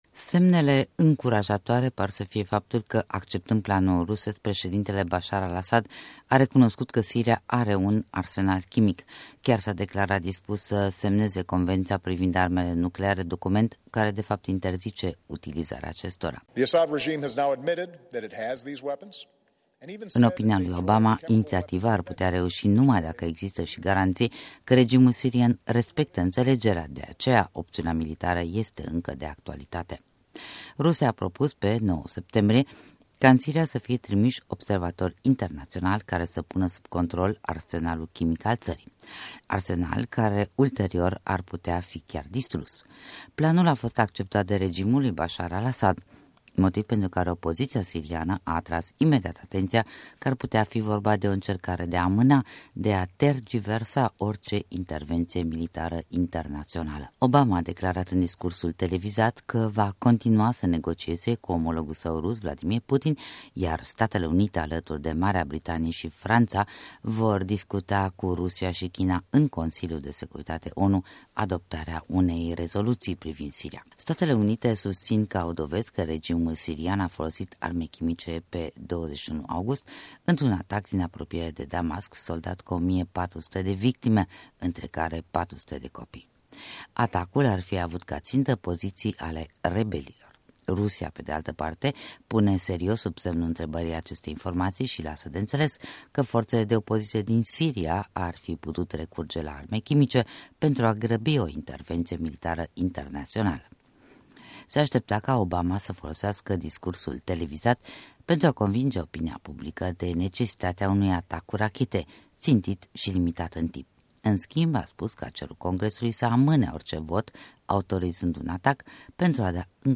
Discursul președintelui Statelor Unite Barack Obama